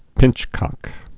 (pĭnchkŏk)